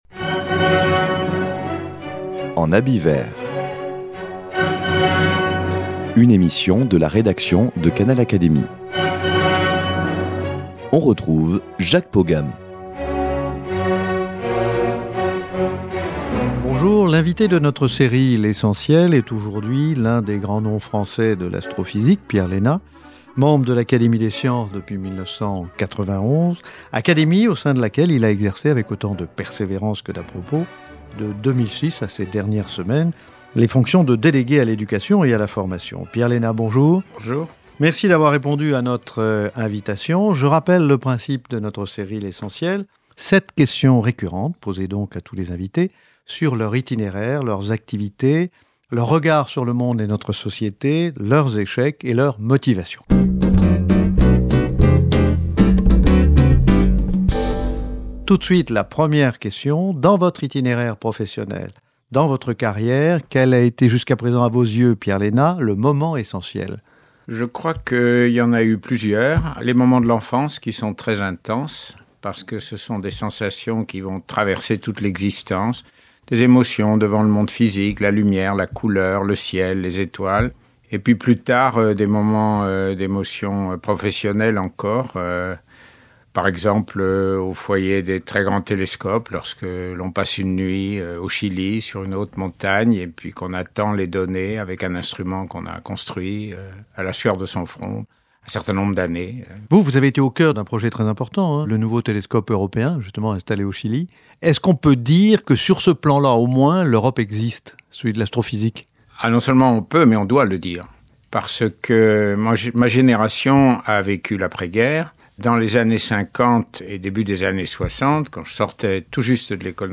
_ L’invité de notre série « l’Essentiel avec... » est aujourd’hui l’un des grands noms français de l’astrophysique : Pierre Léna, membre de l’académie de sciences depuis 1991.